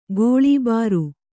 ♪ gōḷībāru